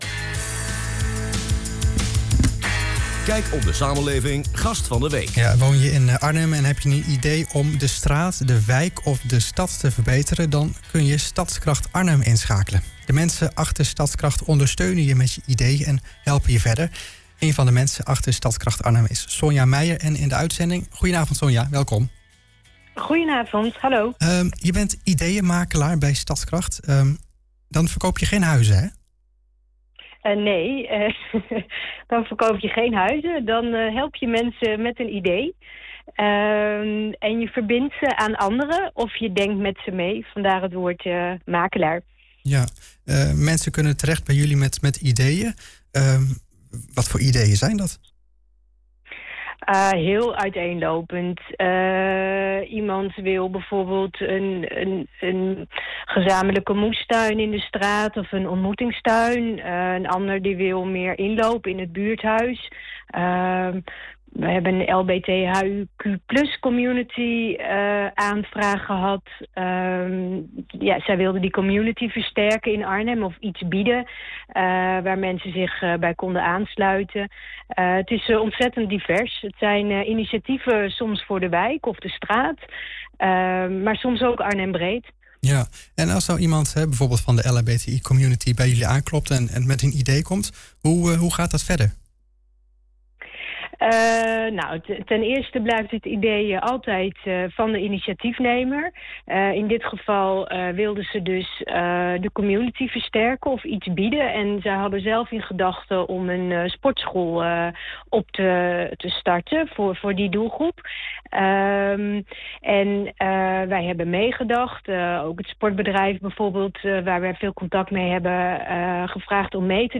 RTV Connect. Luister het interview hier terug: